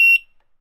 sfx_scan.ogg